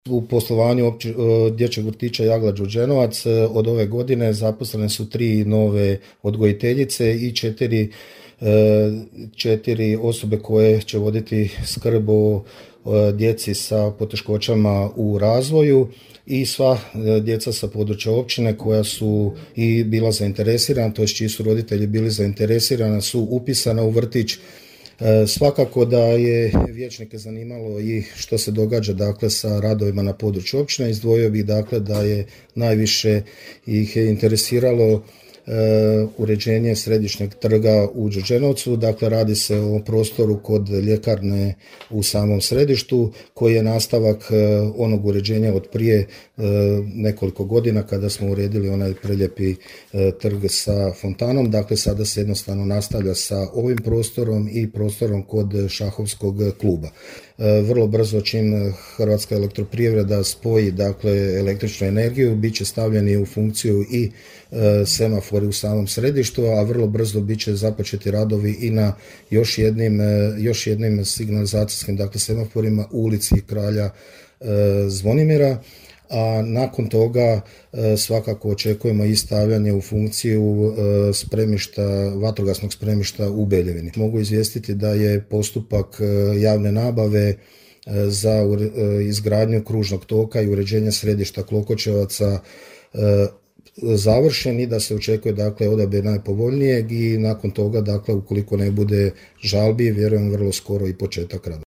Na postavljena pitanja odgovorio je načelnik Hrvoje Topalović: